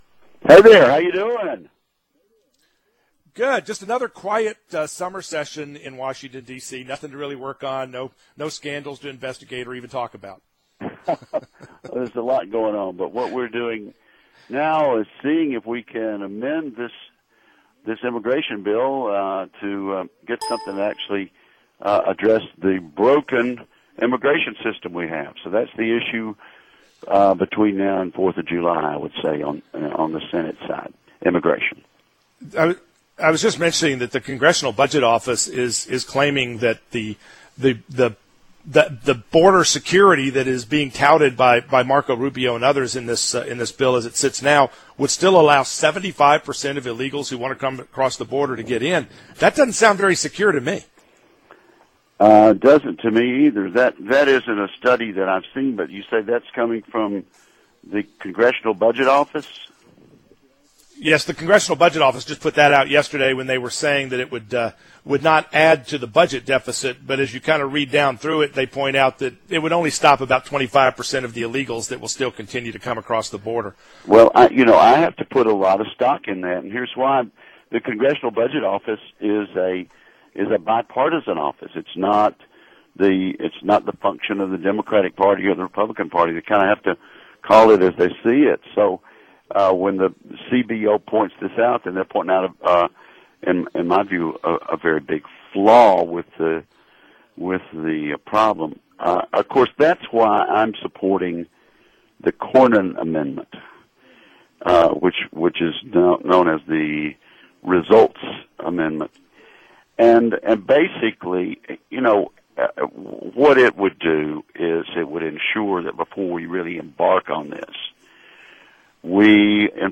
Wicker Interview with SuperTalk Mississippi